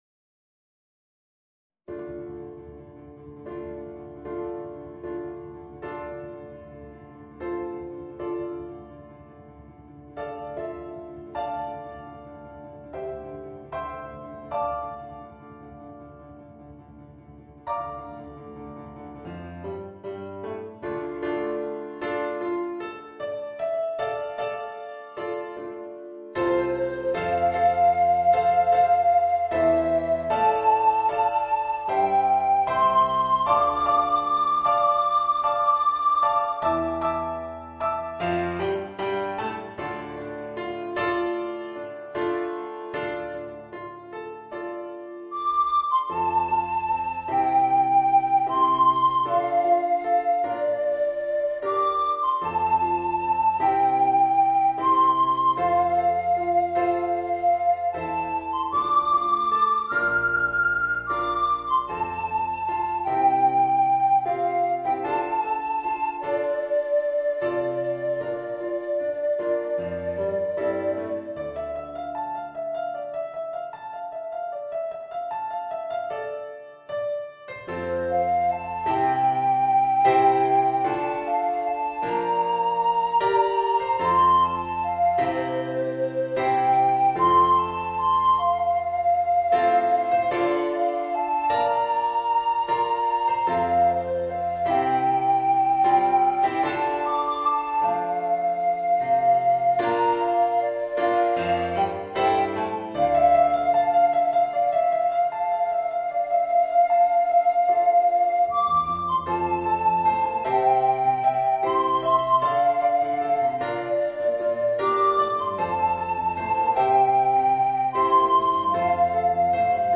Voicing: Soprano Recorder and Piano